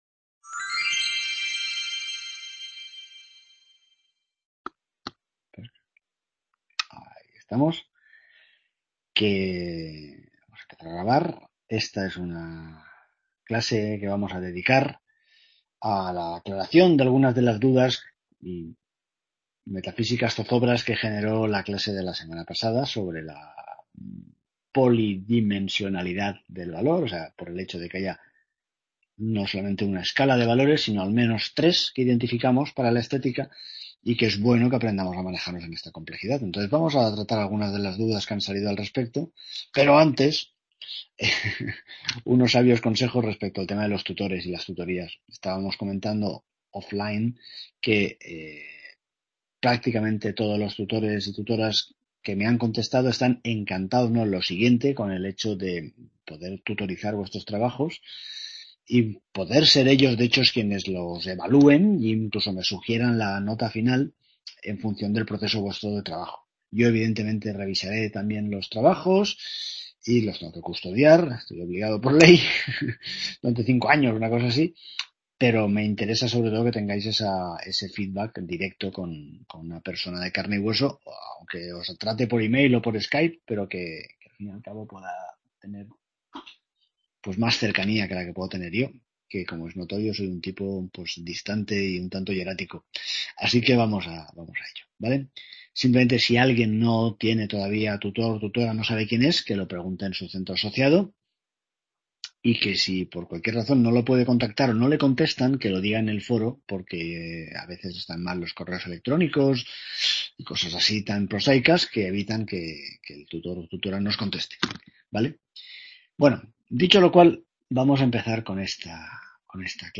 Clase de aclaración de dudas sobre el valor y su…